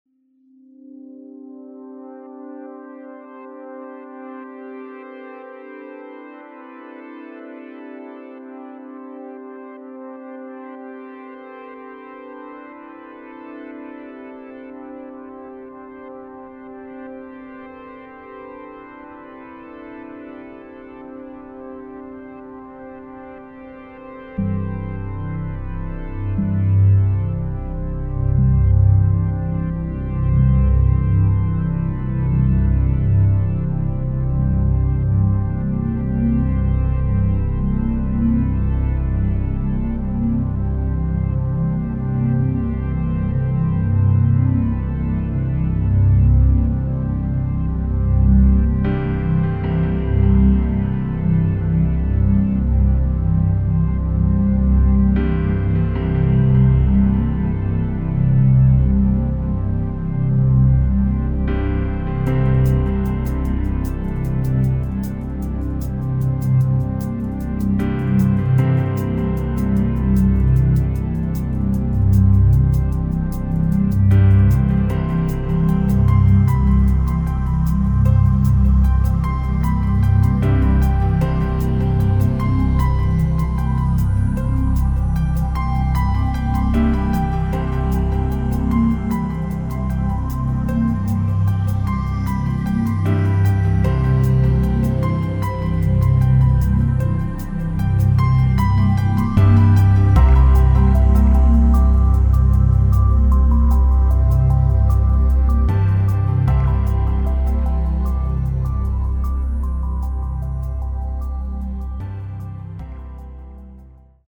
electronic music
concentration frequencies for super-learning